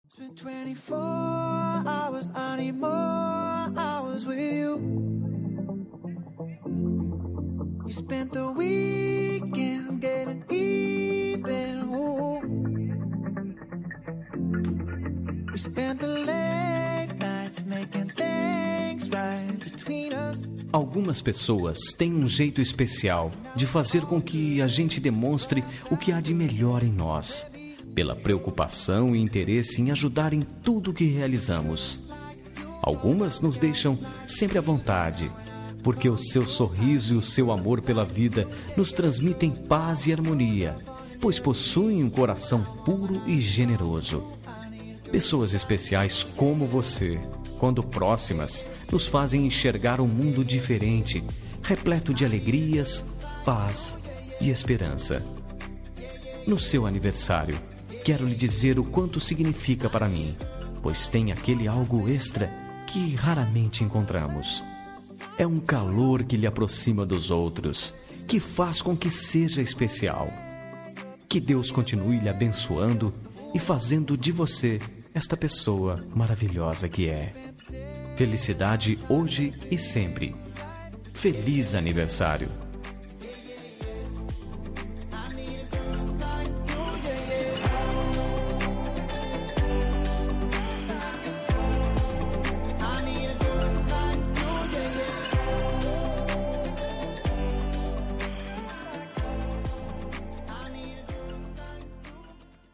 Aniversário de Pessoa Especial – Voz Masculina – Cód: 02348